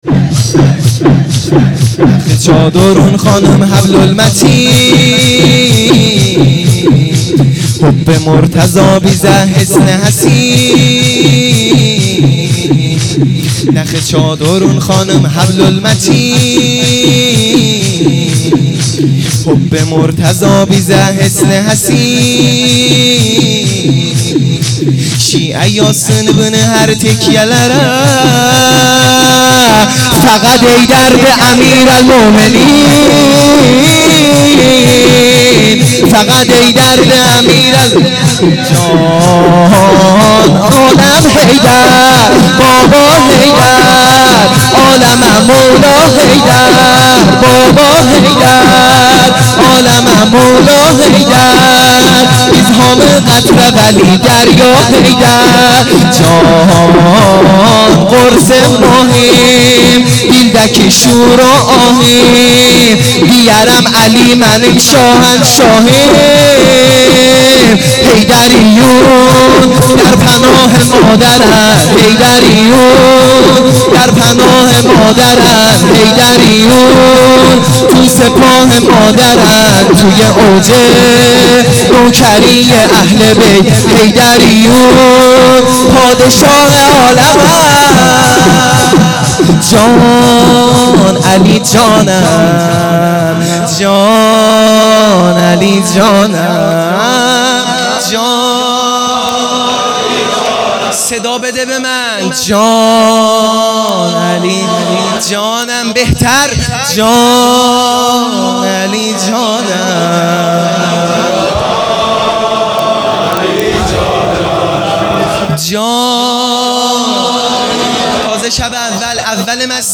شور | حیدریون پادشاه عالمند